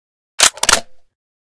unjam_empty.ogg